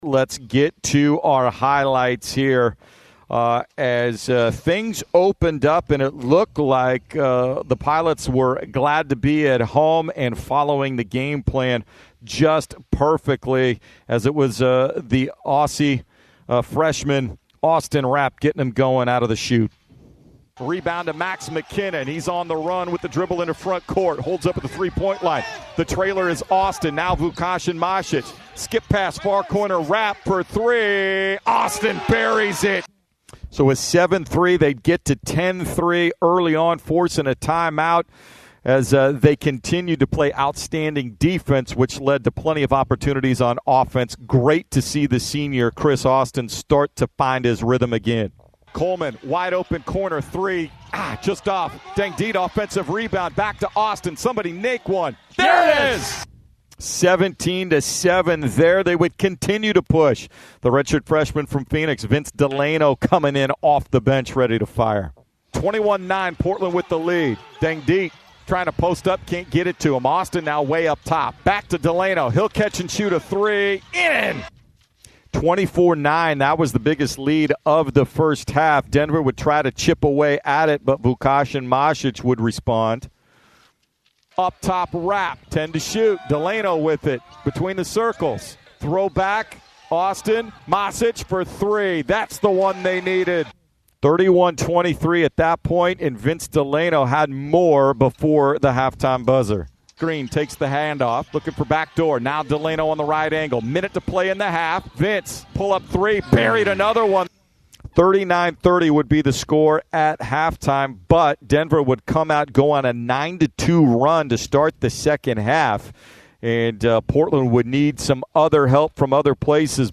Men's Hoops Radio Highlights vs. Denver